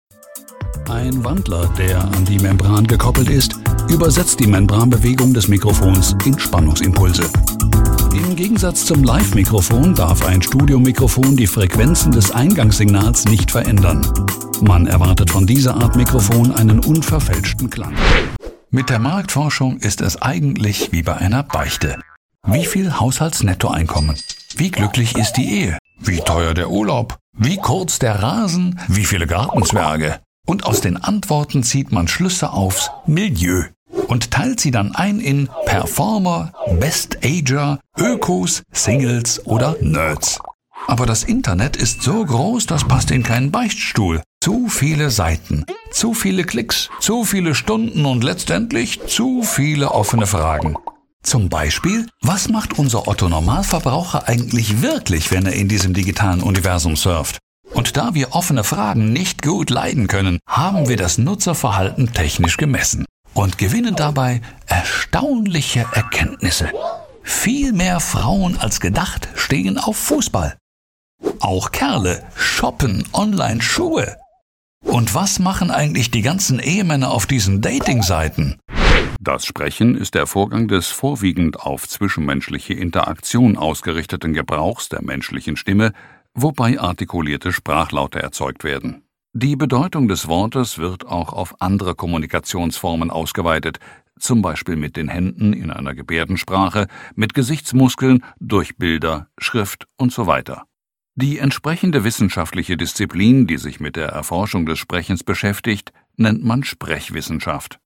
Bekannte, dynamische, kräftige und markante Stimme; mit hohem Wiedererkennungswert in voller warmer Stimmlage.
Professioneller deutscher Sprecher (seit 1994) mit eigenem Studio.
Sprechprobe: eLearning (Muttersprache):
E-Learning-Sachtext--Collage_Projekt.mp3